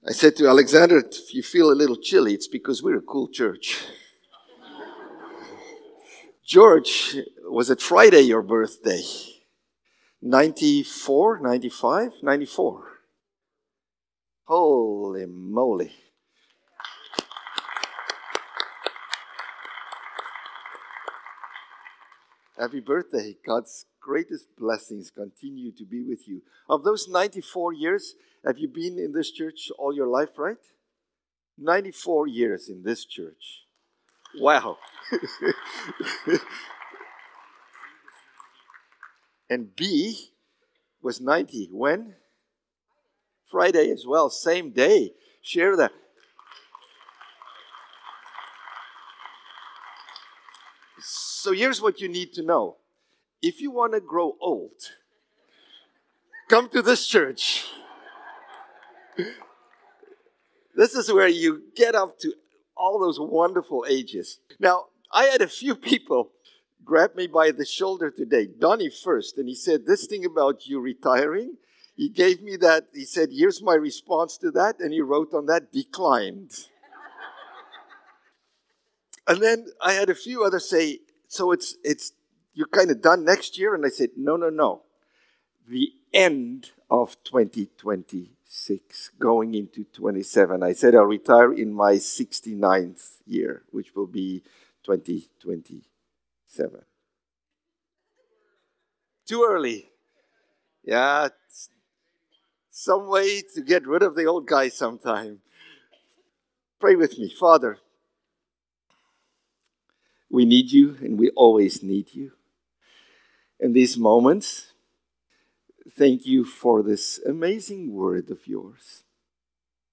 December-22-Sermon.mp3